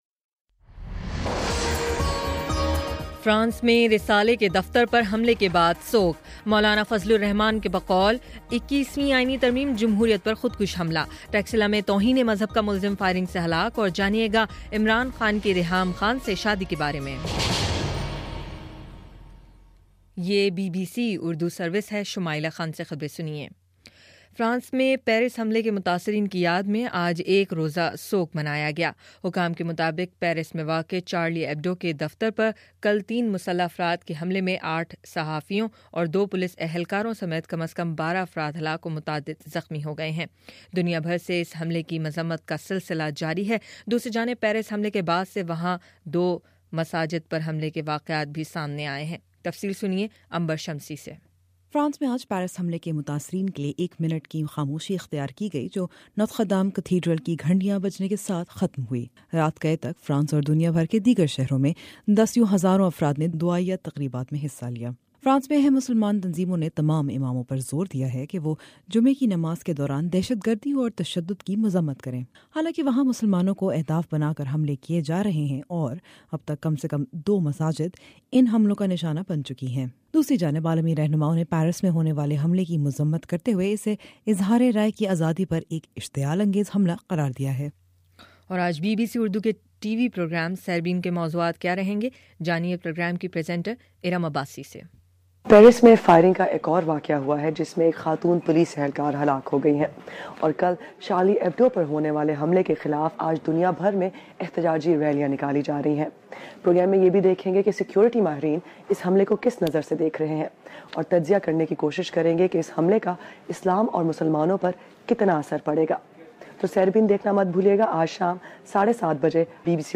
جنوری 08: شام چھ بجے کا نیوز بُلیٹن